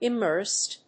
音節im・mérsed 発音記号・読み方
/ˌɪˈmɝst(米国英語), ˌɪˈmɜ:st(英国英語)/